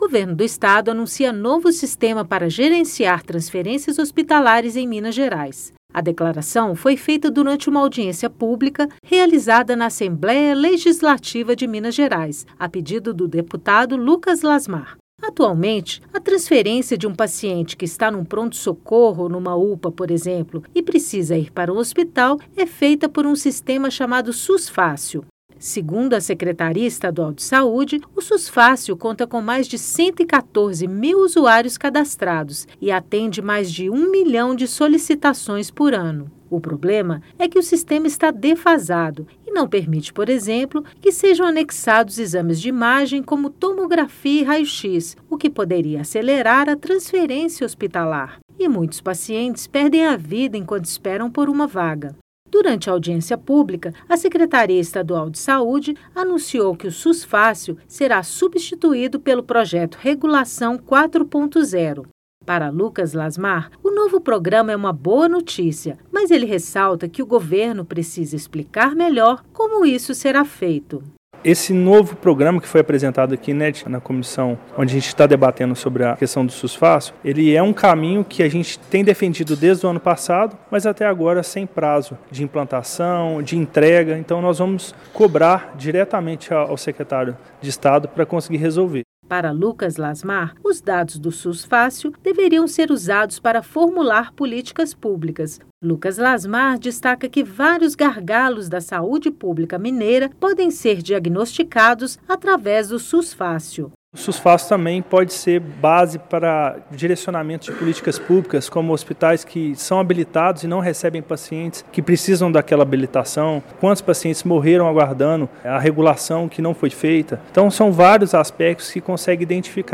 Governo do Estado anuncia novo sistema para gerenciar transferências hospitalares em Minas em uma audiência pública presidida por Lucas Lasmar.
Boletim de Rádio